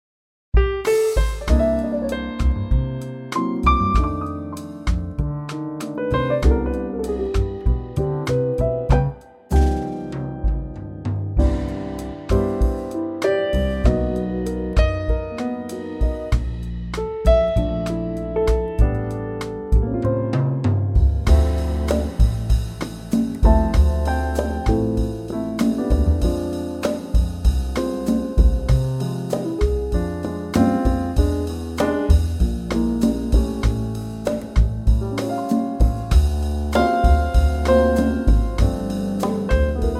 Unique Backing Tracks
key C
PIANO SOLO REMOVED!
key - C - vocal range - G to A
in a lovely Trio arrangement